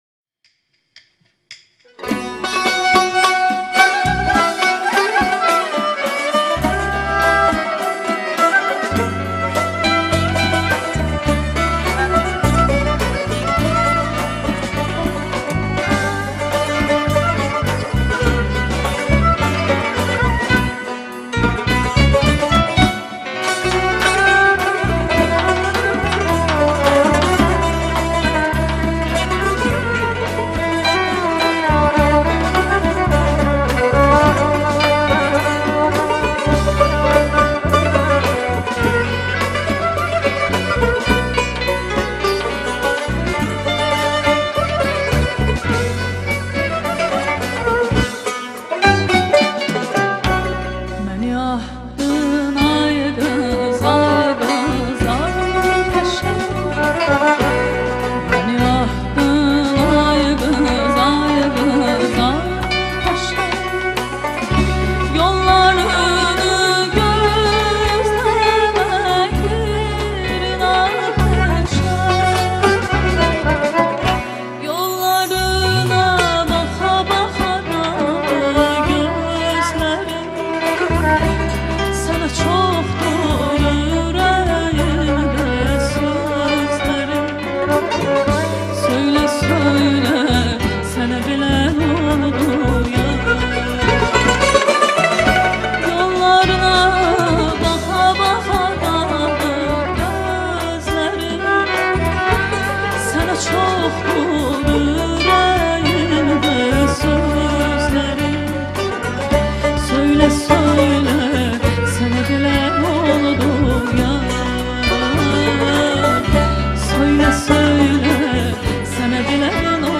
Azerbaycan xalq mahnı ve tesnifleri